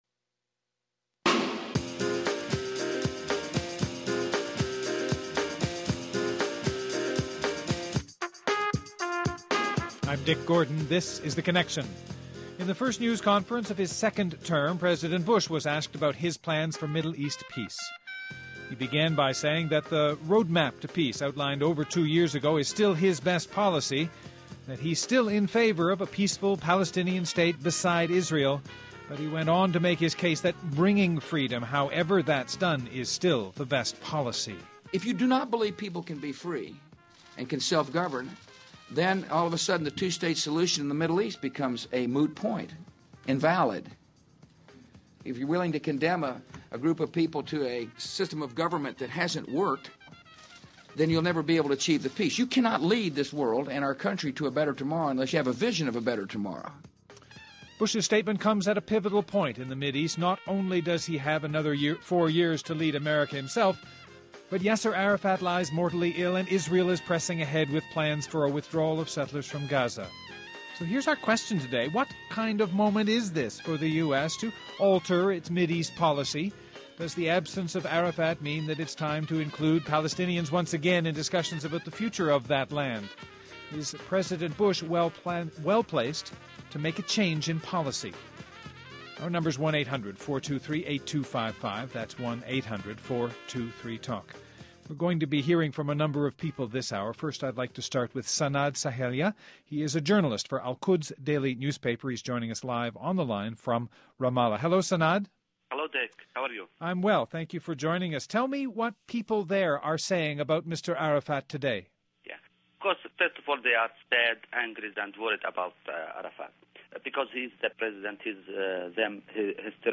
Hear a discussion on the future of U.S. policy and peace negotiations in the Middle East after Arafat.